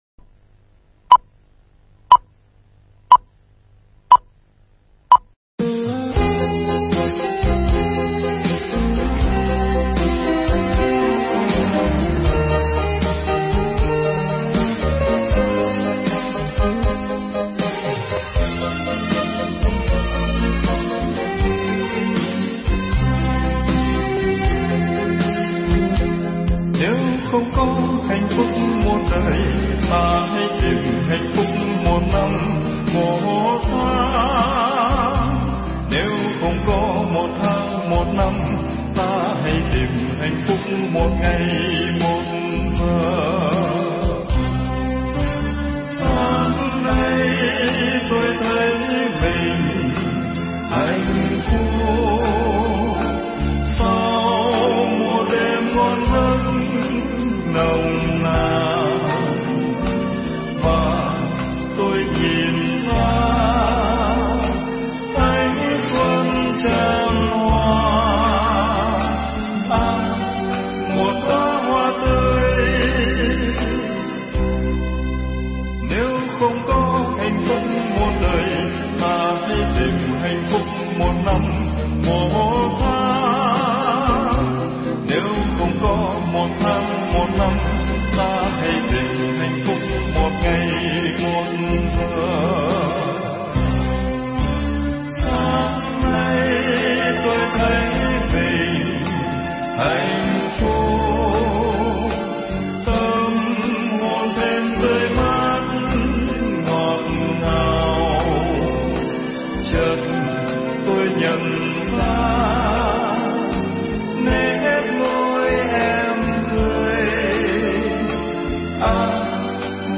tại chánh điện chùa Quảng Tế (Huế)